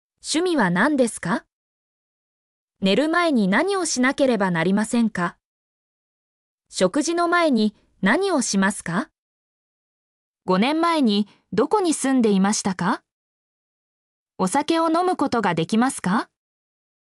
mp3-output-ttsfreedotcom-42_ebUJ4wds.mp3